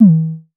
REDD PERC (14).wav